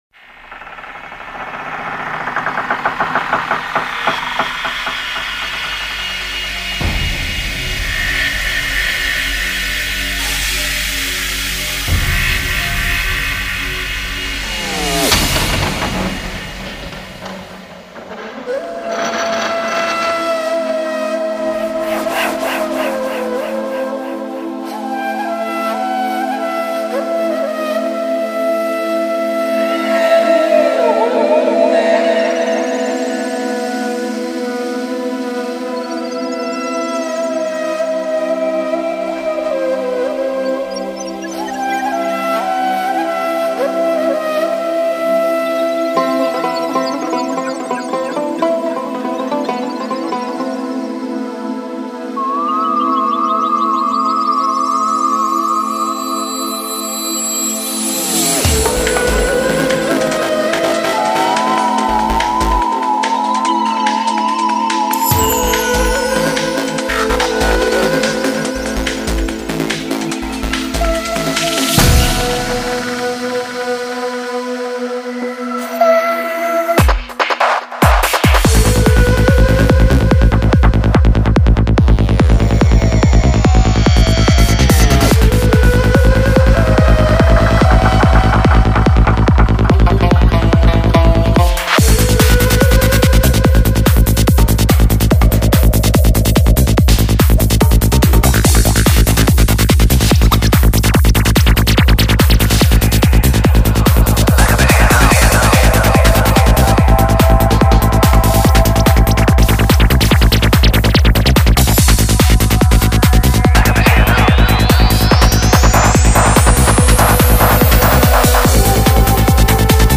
productor de músicas electrónicas de estilo psy-trance